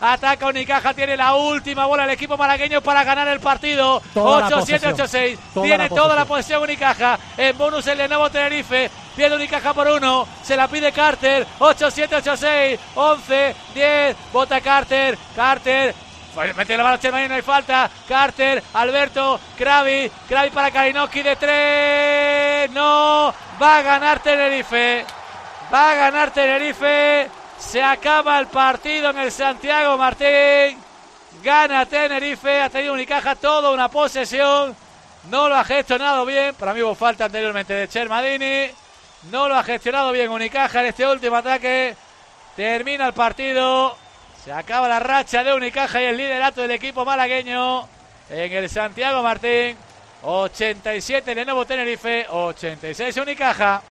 Así hemos narrado el final del partido entre Lenovo Tenerife y Unicaja de Málaga (87-86)